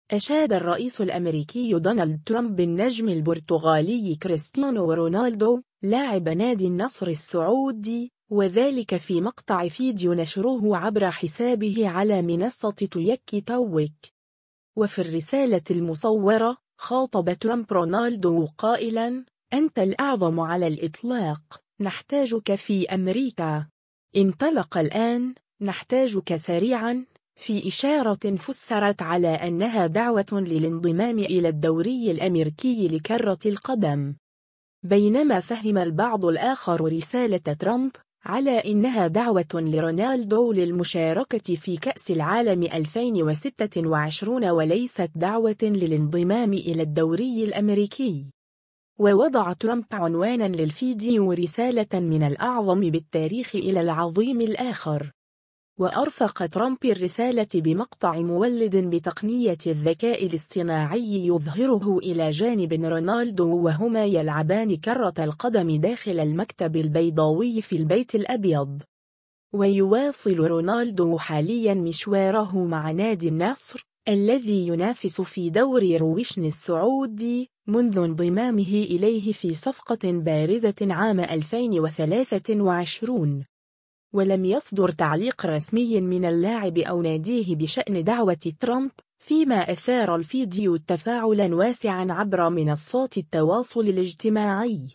و في الرسالة المصوّرة، خاطب ترامب رونالدو قائلا : “أنت الأعظم على الإطلاق، نحتاجك في أمريكا.. انطلق الآن، نحتاجك سريعا”، في إشارة فُسّرت على أنها دعوة للانضمام إلى الدوري الأميركي لكرة القدم.